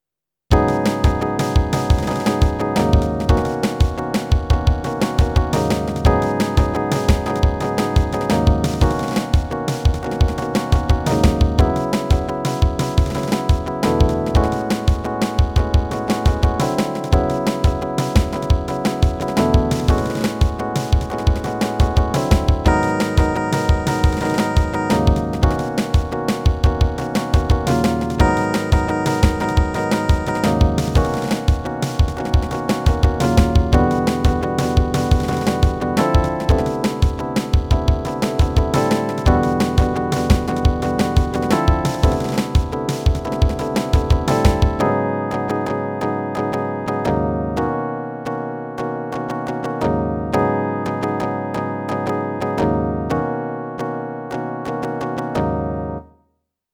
拡張機能カッティングでつくった凡例です。
16beat - cutting.mp3